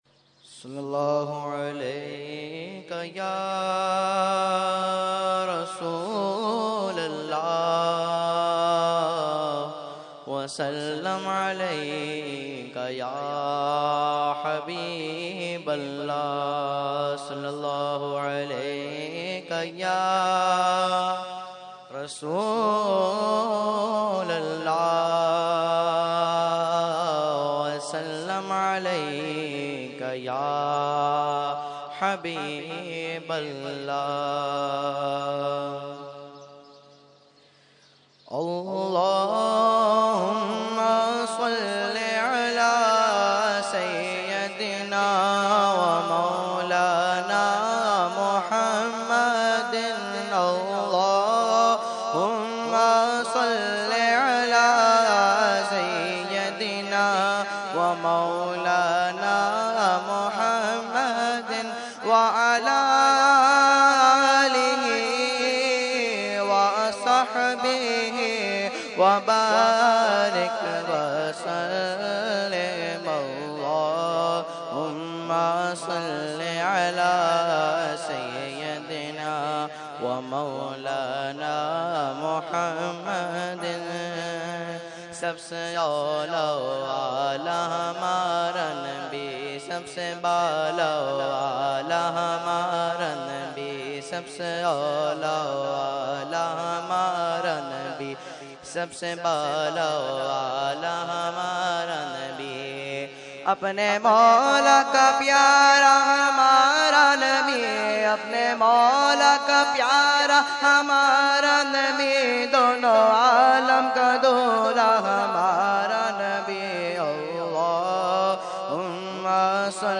Category : Naat | Language : UrduEvent : Urs Qutbe Rabbani 2015